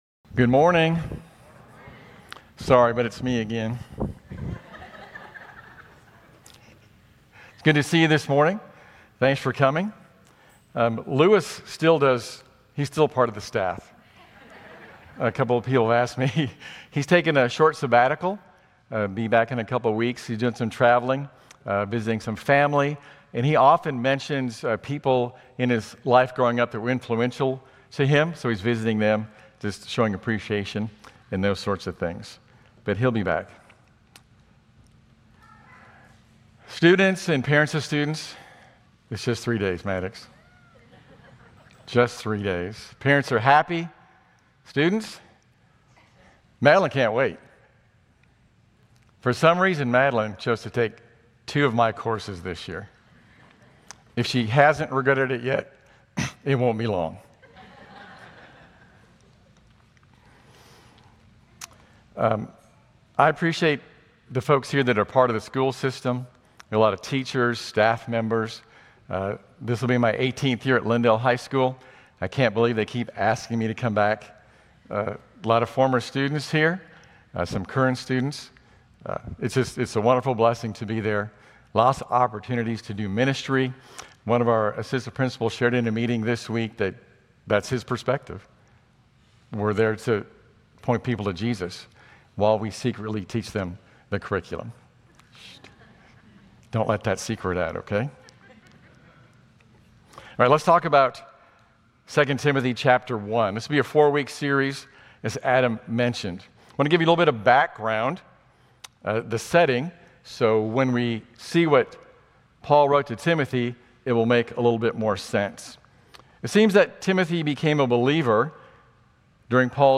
Grace Community Church Lindale Campus Sermons 8_10 Lindale Campus Aug 11 2025 | 00:31:43 1x 00:00 / 00:31:43 Subscribe Share RSS Feed Share Link Embed